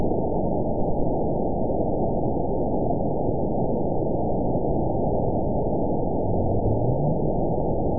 event 919794 date 01/23/24 time 22:54:21 GMT (1 year, 3 months ago) score 9.52 location TSS-AB02 detected by nrw target species NRW annotations +NRW Spectrogram: Frequency (kHz) vs. Time (s) audio not available .wav